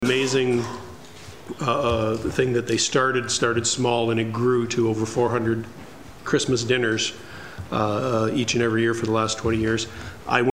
Councillor Phil St. Jean:
Phil-St.mp3